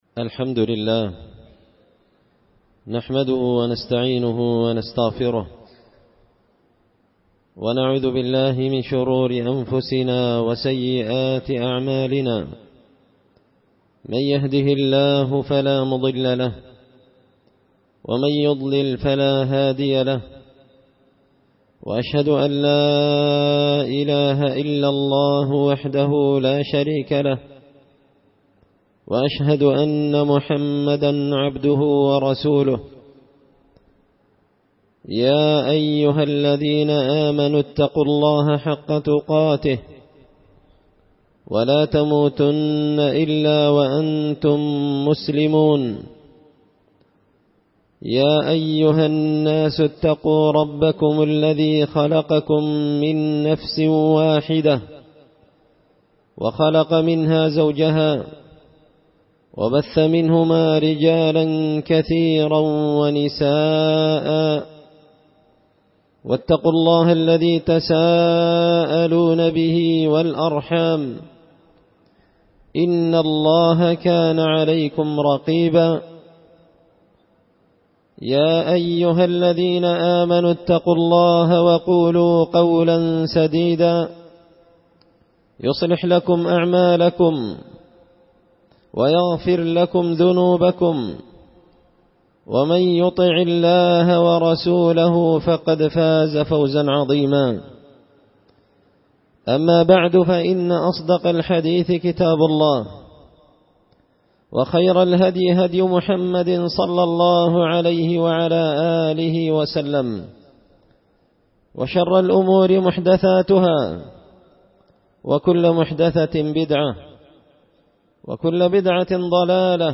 خطبة جمعة بعنوان – المظاهرات شرور ونكبات
دار الحديث بمسجد الفرقان ـ قشن ـ المهرة ـ اليمن